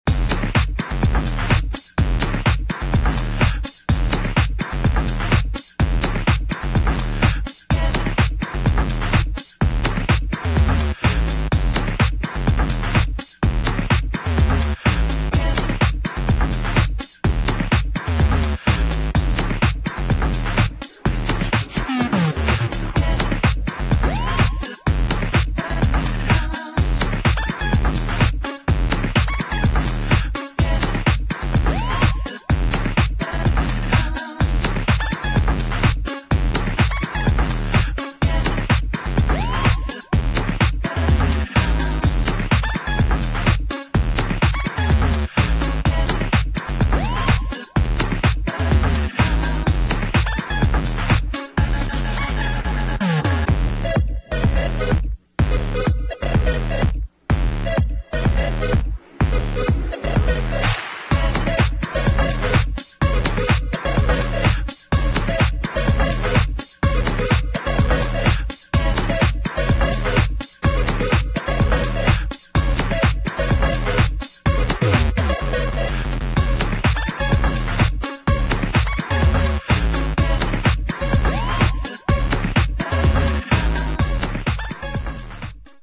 [ HOUSE / ELECTRO POP ]